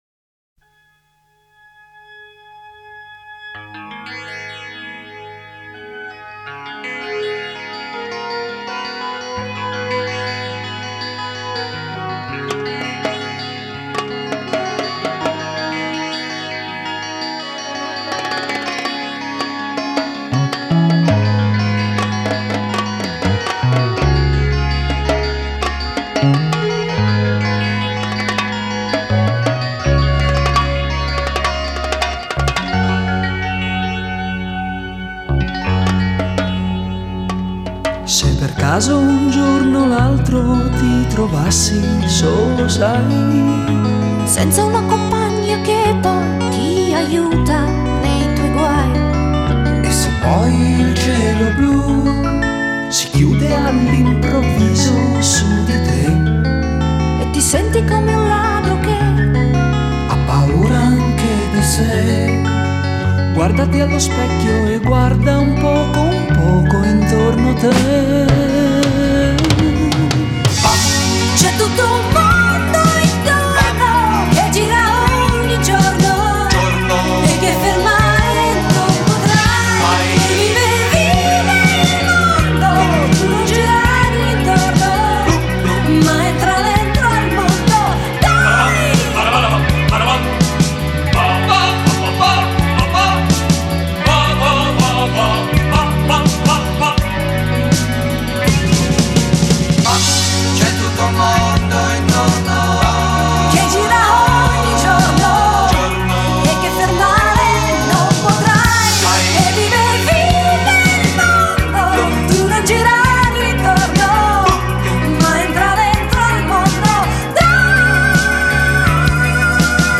Genre: Pop, Pop-Rock, New Wave, Electronic